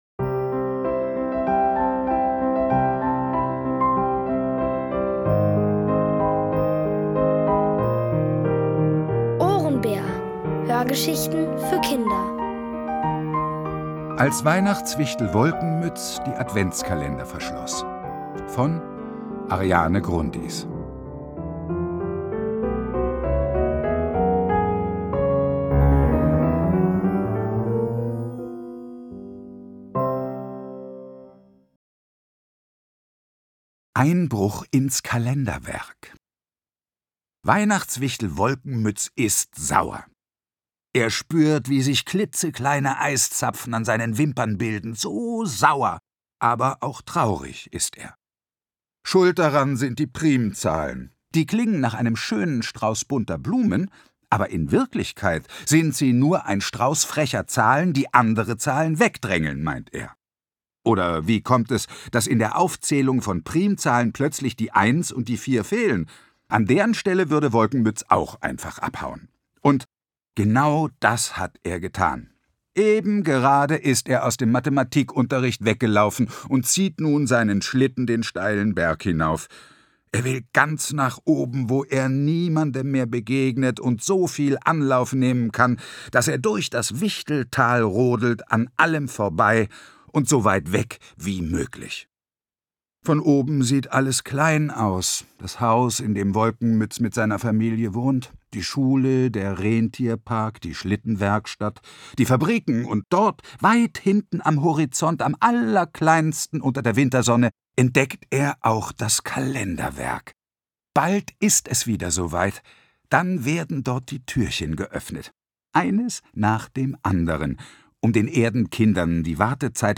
Es liest: Heikko Deutschmann.▶ M…